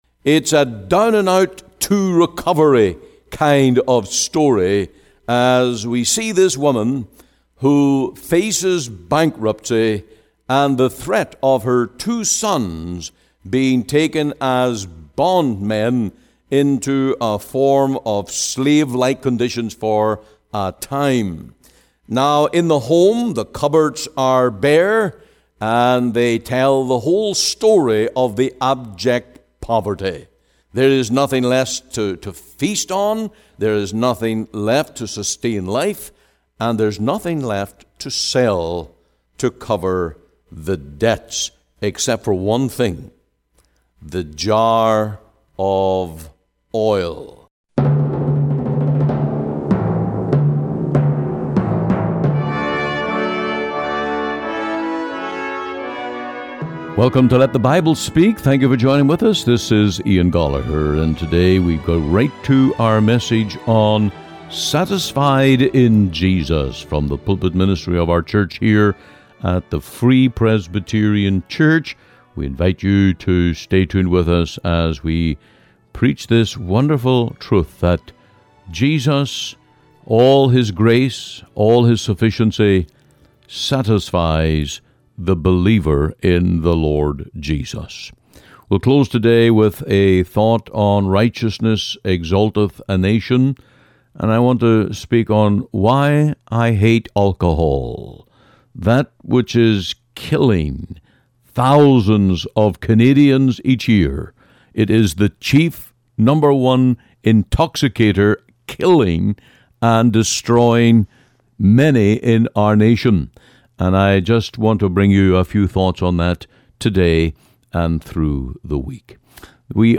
Sermons | The Free Presbyterian Church in Cloverdale